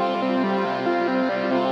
SaS_MovingPad03_140-C.wav